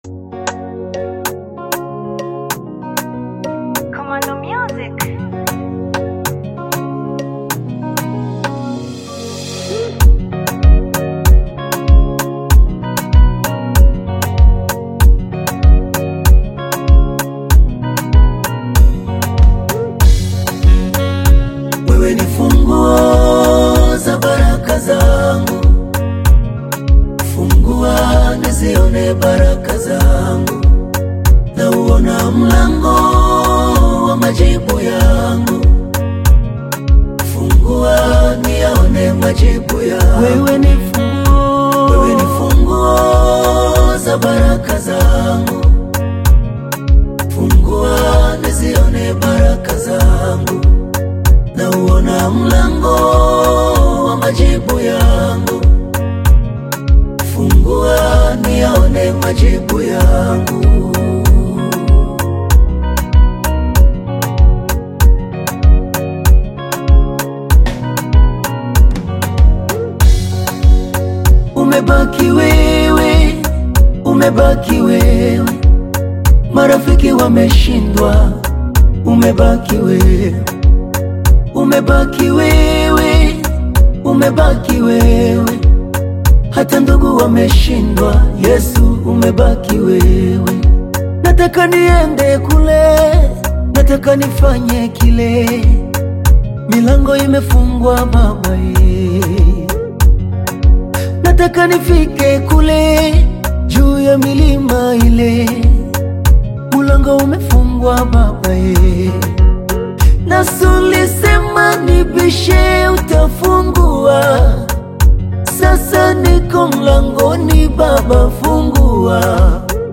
AudioGospel
uplifting Tanzanian gospel praise anthem